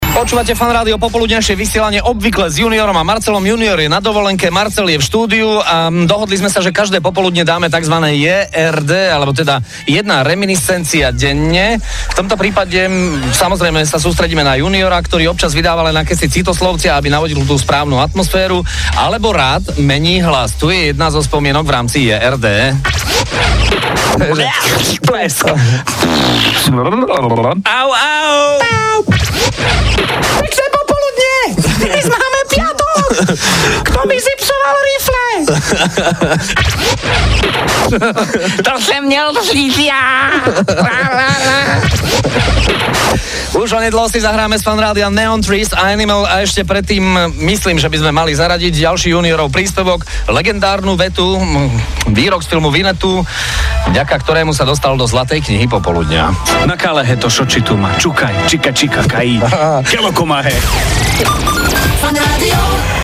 hlasové variácie z vysielanie...